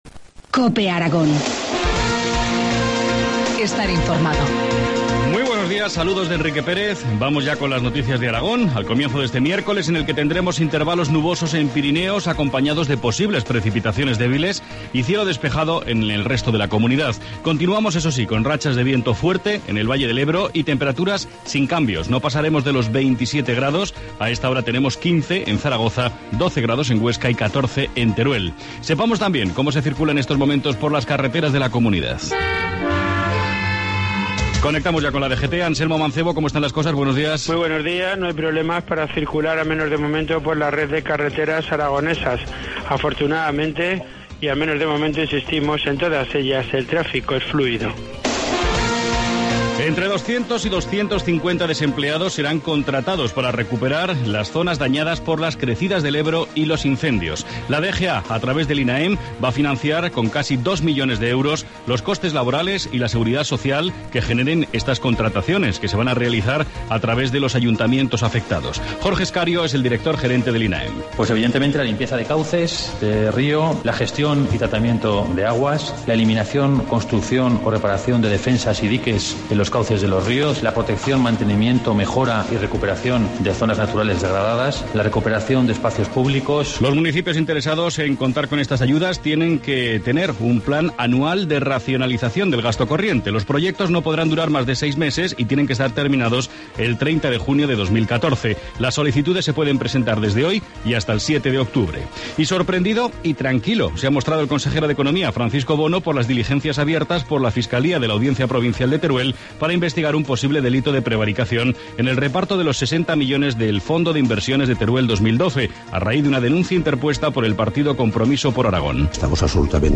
Informativo matinal, miércoles 18 septiembre, 2013, 7,25 horas